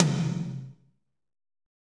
Index of /90_sSampleCDs/Northstar - Drumscapes Roland/DRM_Fast Rock/TOM_F_R Toms x
TOM F RHI0EL.wav